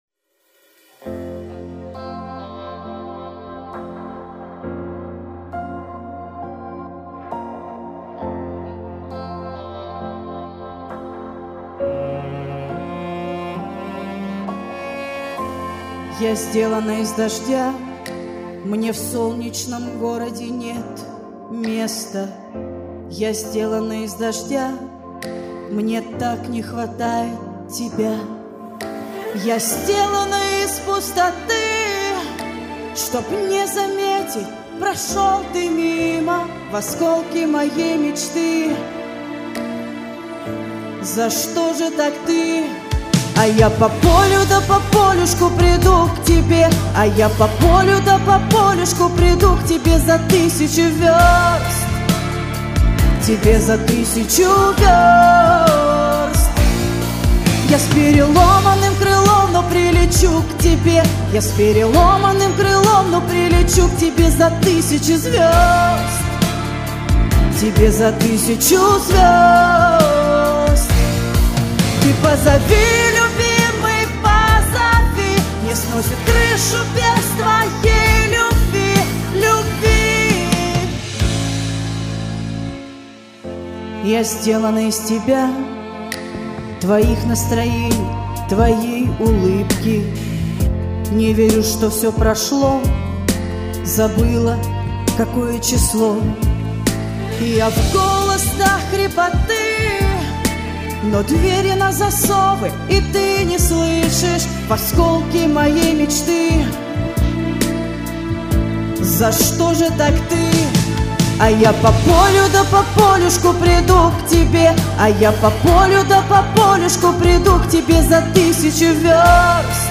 Послушала и записала, как говорится первым дублем ))